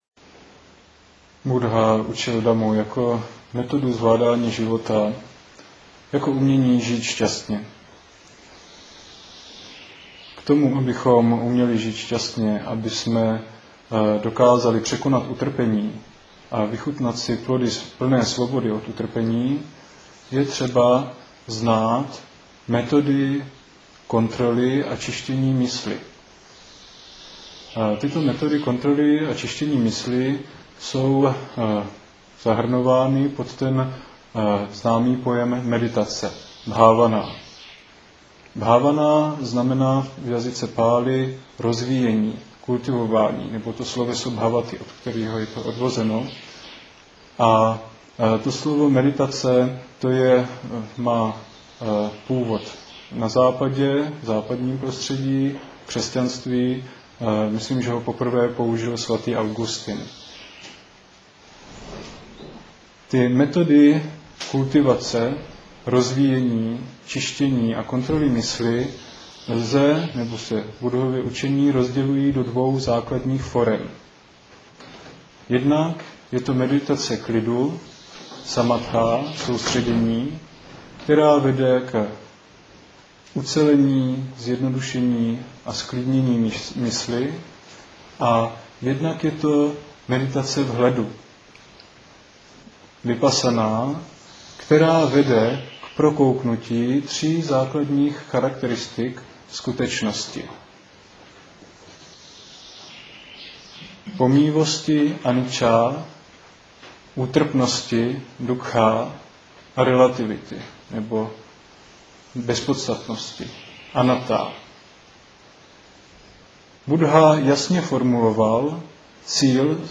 Proč Buddha učil methody soustředění Dibba-Vihára a Brahma-Vihára, jaké se užívají předměty, přípravky Kasina a rozjímání Anussati atd . Závěrečný přednes na kurzu Dibba-Vihára